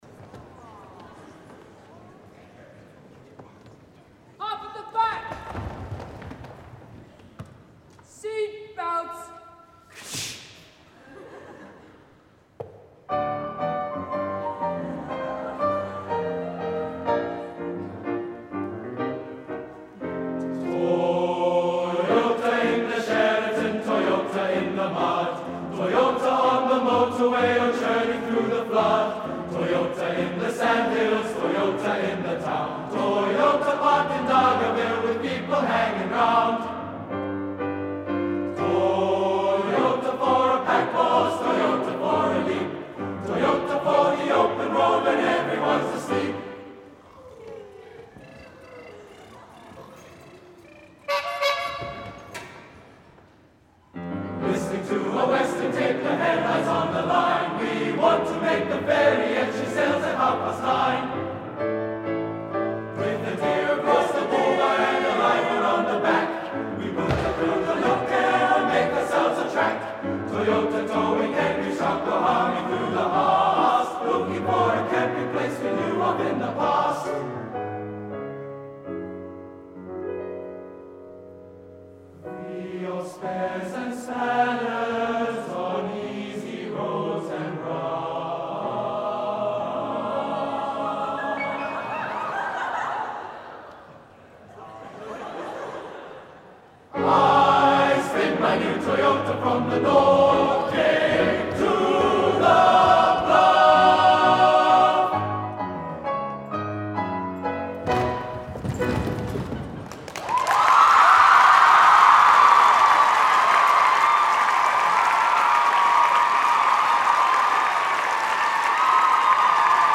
Recordings from The Big Sing National Final.